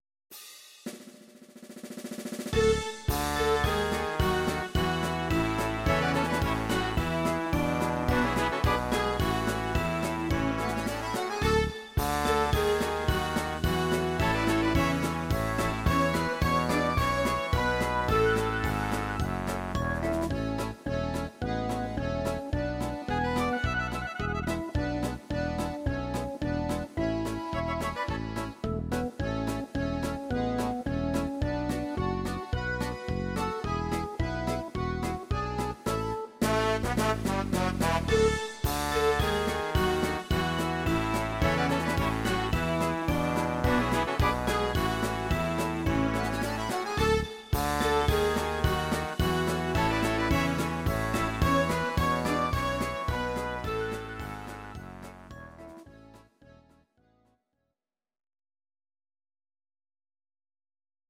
Audio Recordings based on Midi-files
German, 1960s